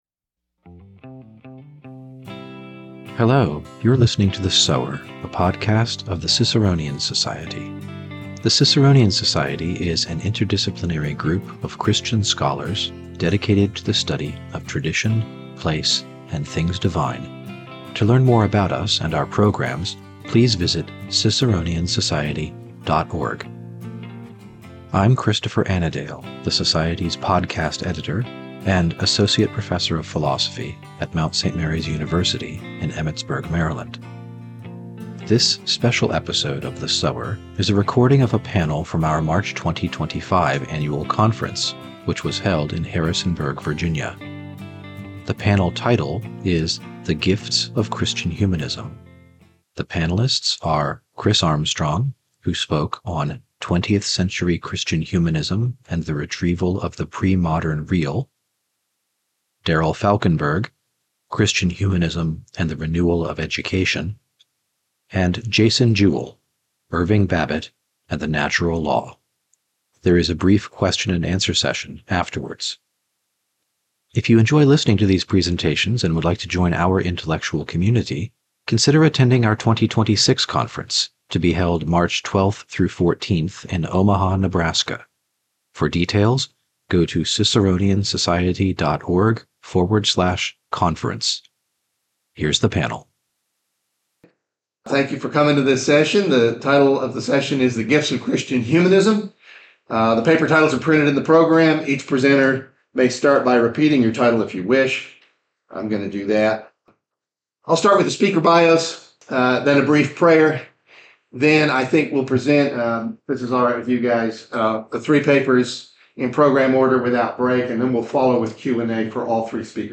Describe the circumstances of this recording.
This special episode is a recording from our 2025 Conference in Harrisonburg, Virginia.